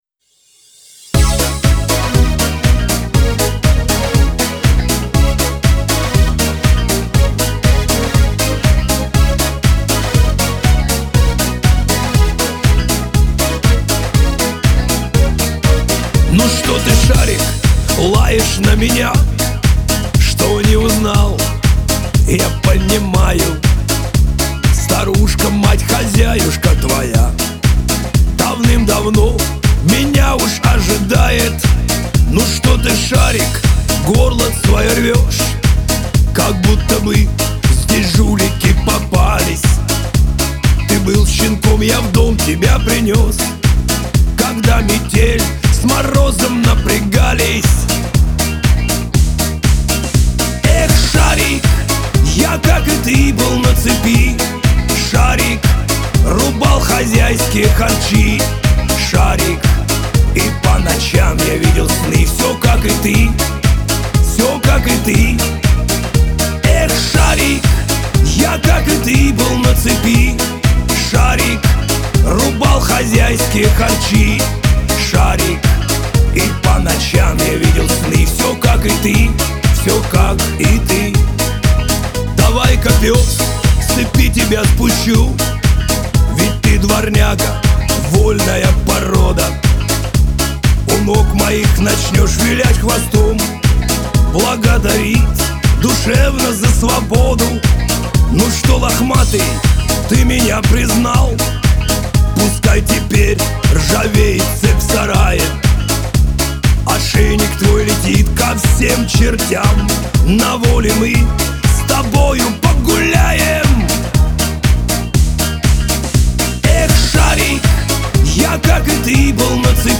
Категория: Шансон песни
шансон музыка